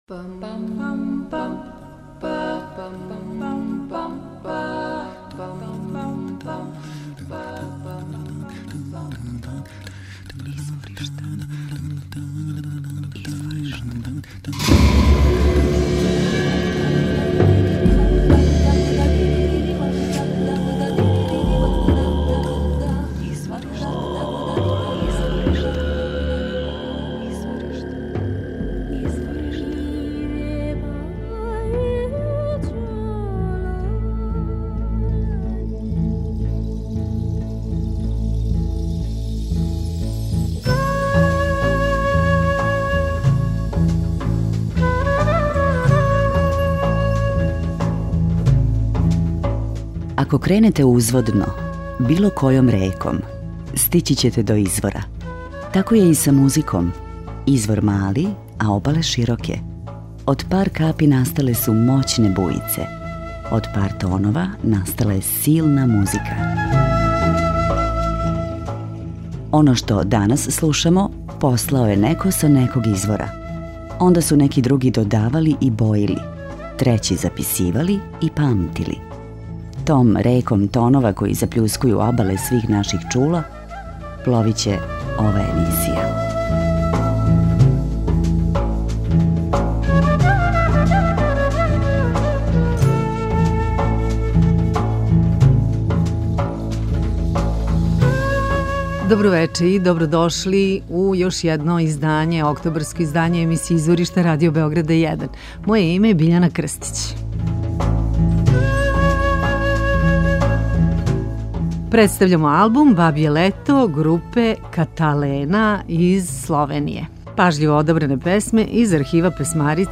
уникатни пост-фолк рок стил
неколико песама које изводи овај веома занимљив оркестар